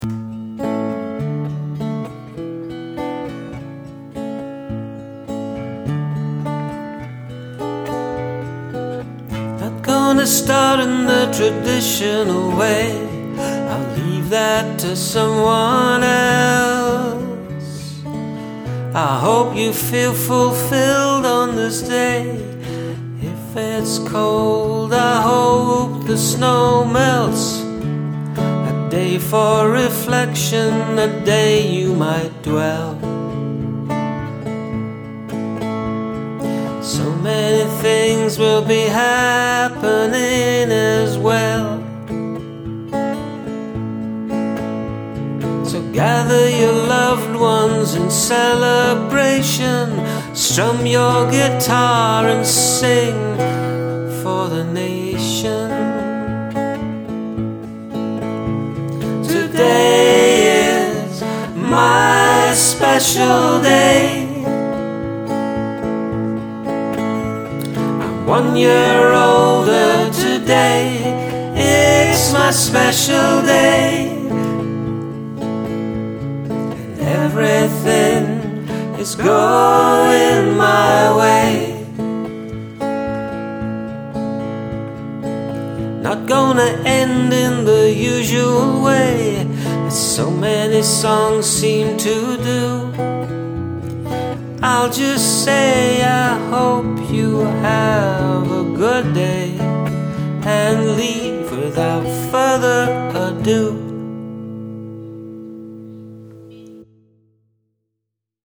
I love the guitar work. The abrupt ending makes me curious.
Really nice, somehow I always like electric guitar and voice when it's done well, which is not easy I think, but you do it here.
Your sensitive voice really Suits these sweet öyrics